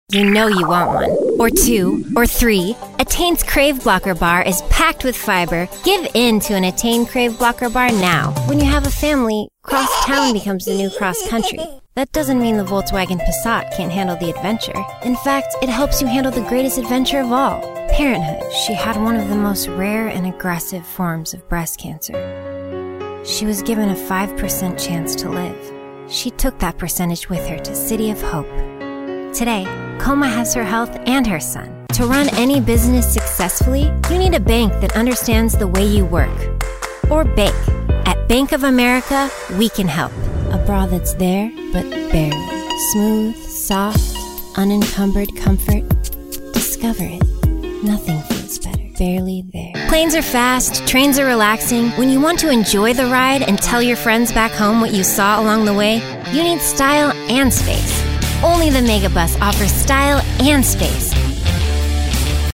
Adult, Young Adult
Has Own Studio
southern us
standard us
commercial
authoritative
friendly
smooth
warm
well spoken